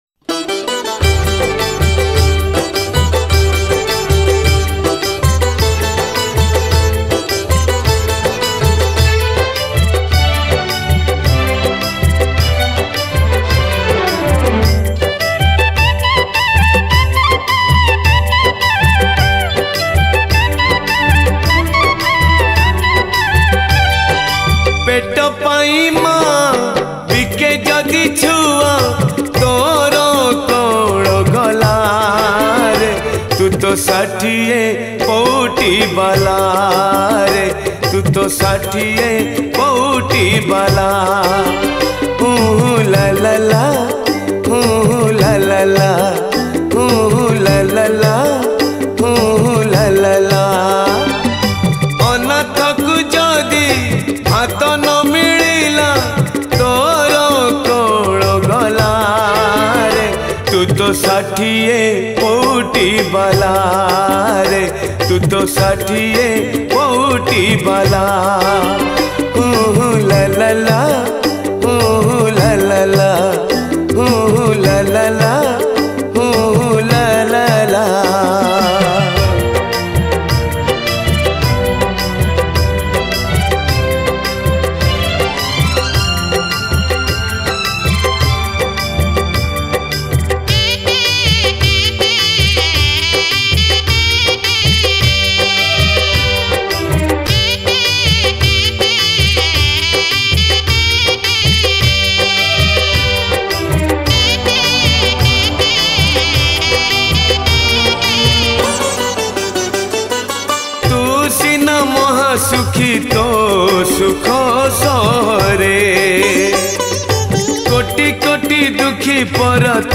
Category : Ratha Yatra Odia Bhajan 2000-21